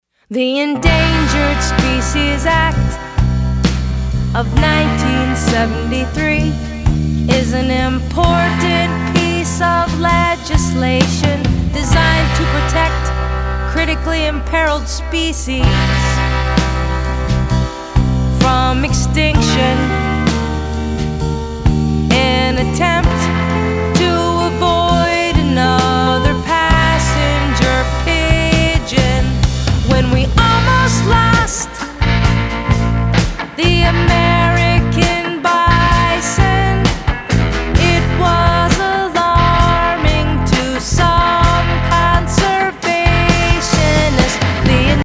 singable tunes and danceable rhythms